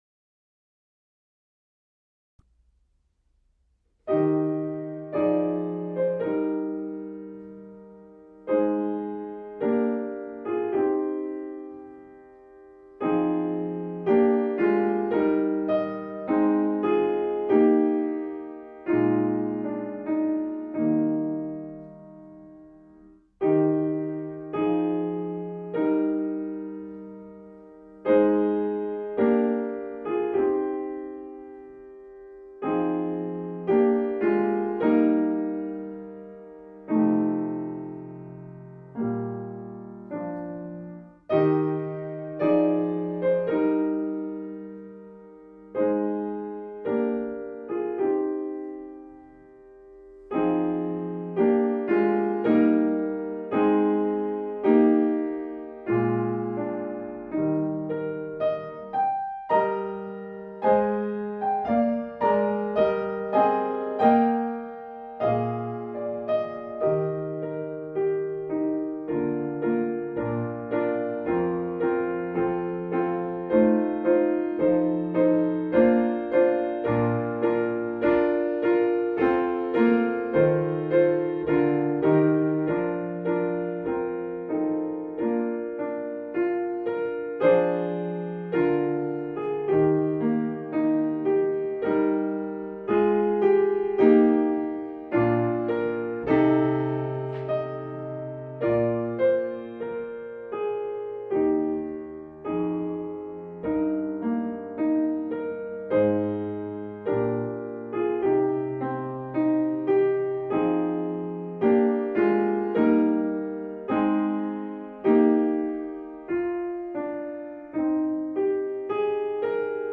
Hier findet ihr Noten, Begleitstimmen in mittlerer und tiefer Lage, ein Demo und eine Einführung von mir mit Tipps, wie ihr euch diese Arie erarbeiten könnt.